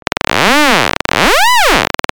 At the time i was investigating different waveforms for phase increment distortion in the context of pulsar synthesis to shape the phase of the pulsaret for a frequency trajectory per grain. The one which by far sounded the best (and thats kind of cute <3) was the halfAHeart window.